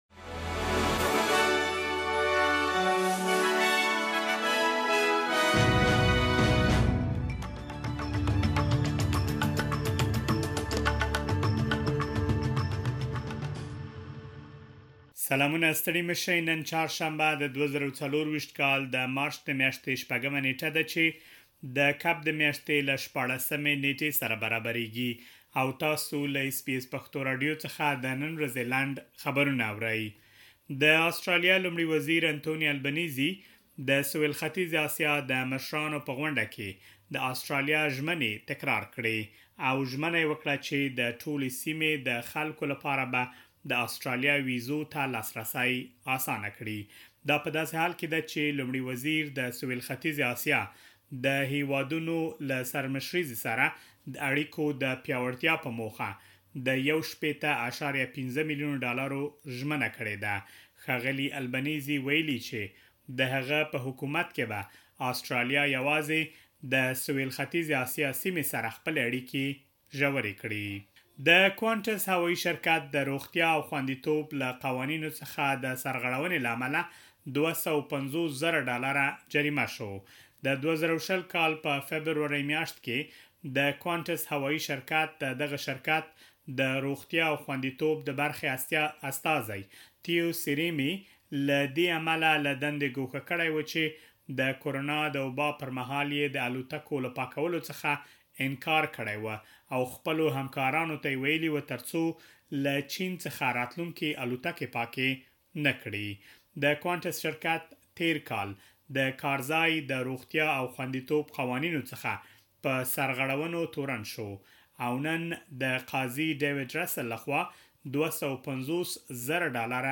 د اس بي اس پښتو راډیو د نن ورځې لنډ خبرونه|۶ مارچ ۲۰۲۴